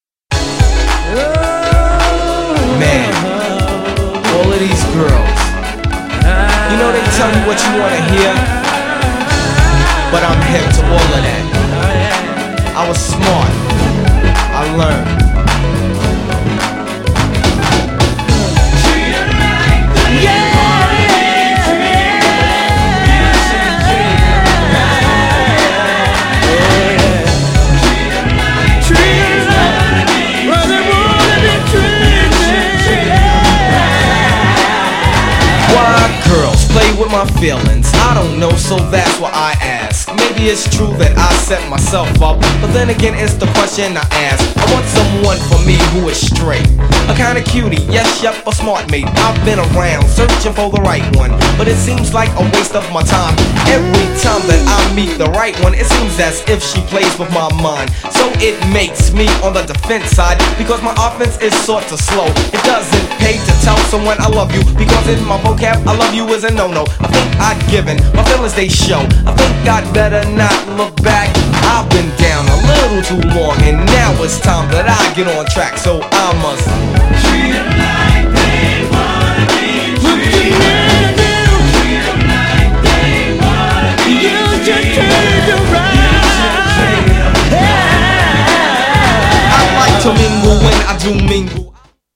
GENRE Hip Hop
BPM 106〜110BPM